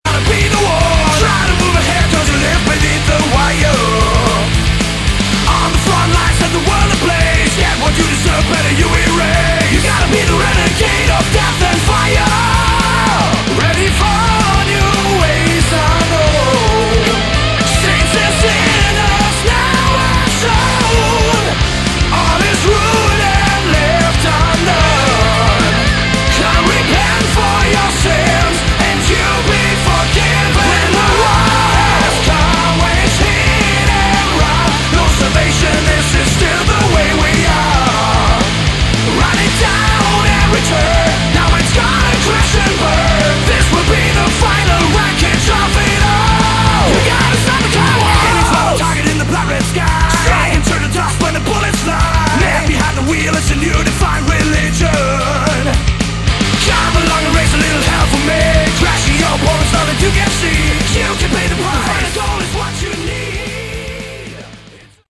Category: Hard Rock
vocals
guitars
bass
drums